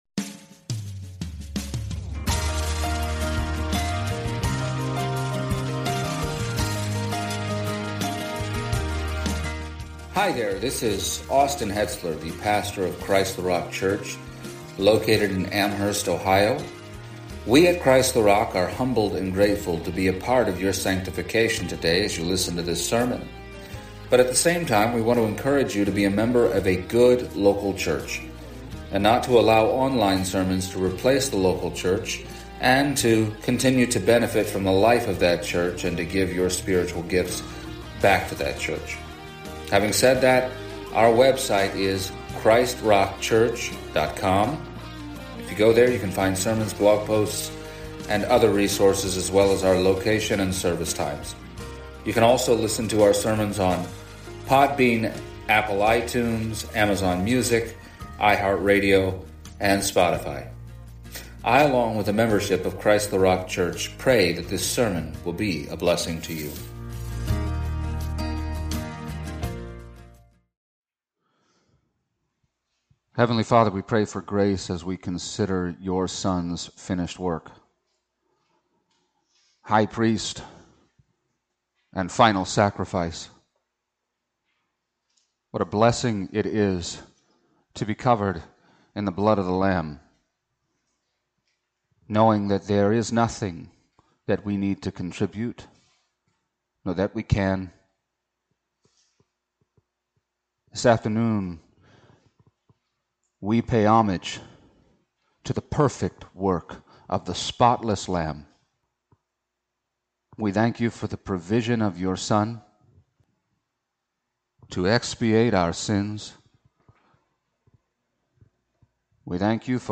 Hebrews 1:3 Service Type: Sunday Morning There were no seats in the temple.